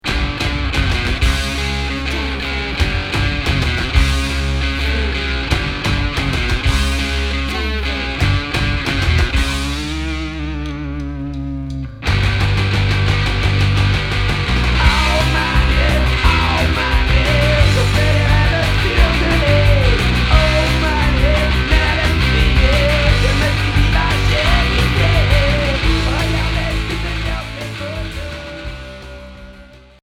Heavy rock Unique 45t retour à l'accueil